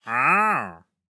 Minecraft Version Minecraft Version 25w18a Latest Release | Latest Snapshot 25w18a / assets / minecraft / sounds / mob / wandering_trader / haggle1.ogg Compare With Compare With Latest Release | Latest Snapshot
haggle1.ogg